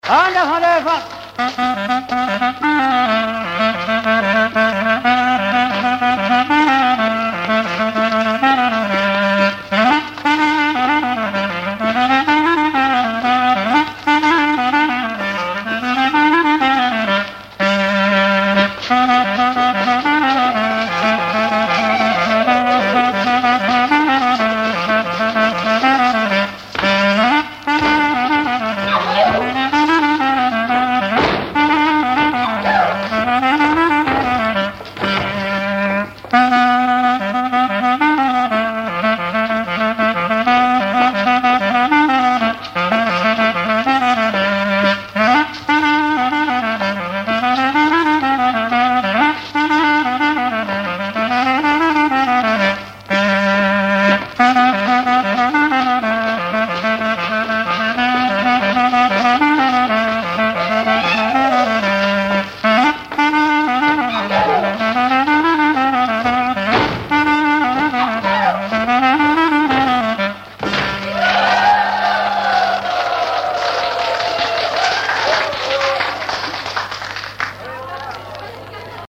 Mémoires et Patrimoines vivants - RaddO est une base de données d'archives iconographiques et sonores.
danse : branle : avant-deux
Catégorie Pièce musicale inédite